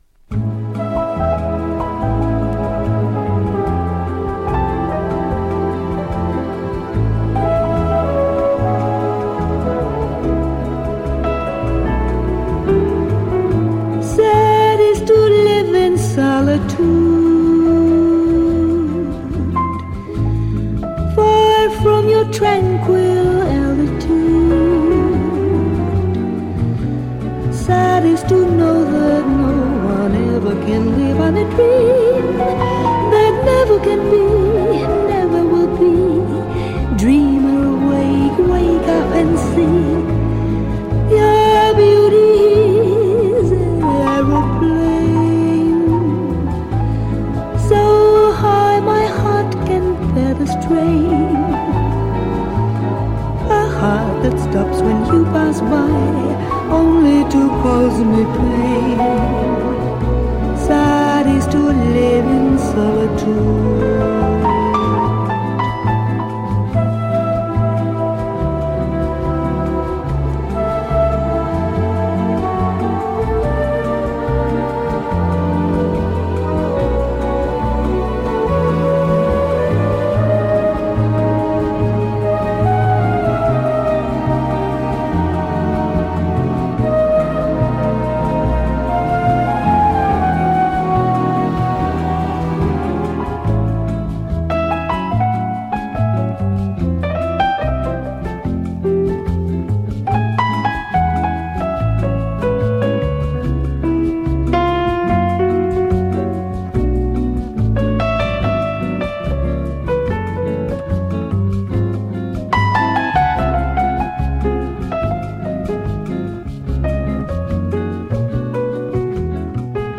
しっとり控えめなストリングスと円熟味を増した彼女の歌声が絶妙にマッチした、 全編お楽しみいただけるリラクシンな一枚です。